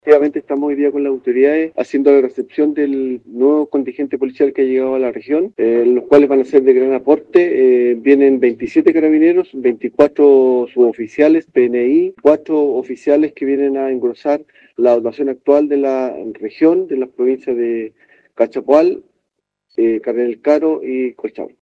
El jefe de la sexta zona de Carabineros, General Guillermo Bohle se encargó del recibimiento desde la plaza de Los Héroes en Rancagua, escuchemos: